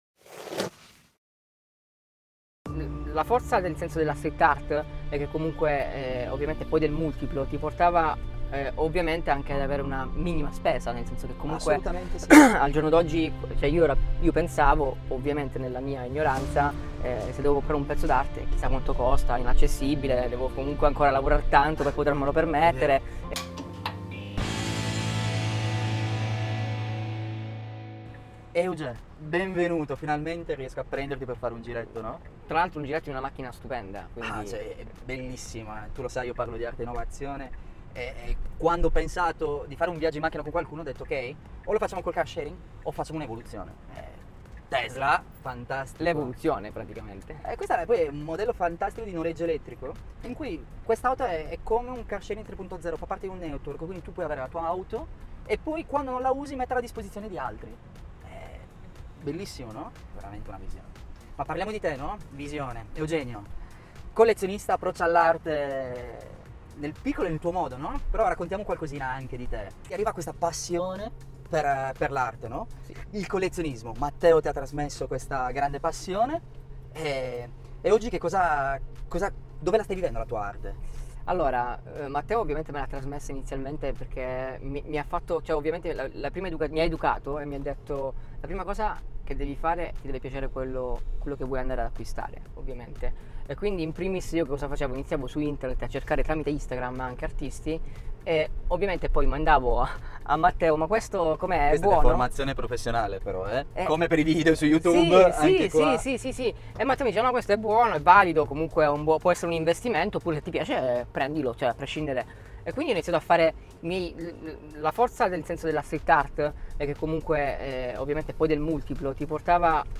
Intervista
Due chiacchiere in viaggio insieme, sull’auto più green e incredibile del momento, una vera e propria opera d’arte su quattro ruote.